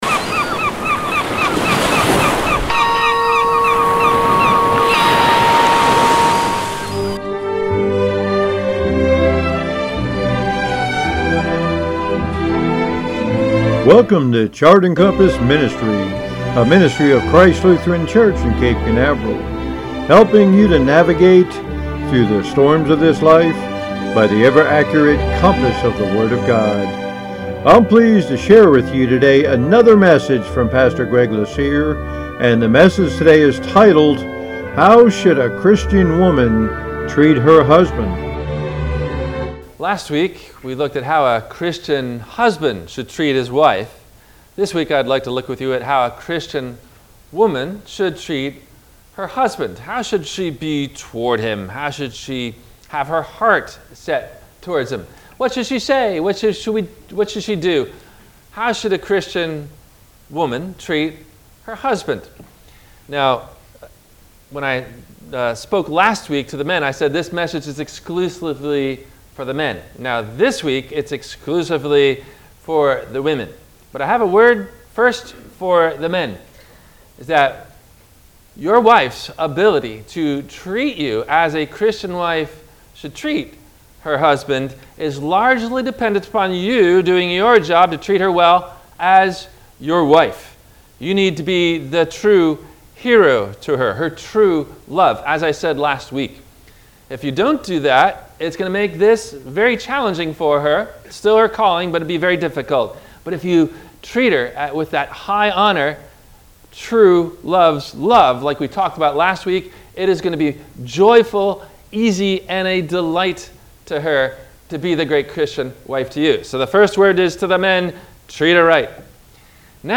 No Questions asked before the Radio Message.
How Should A Christian Woman Treat Her Husband? – WMIE Radio Sermon – October 24 2022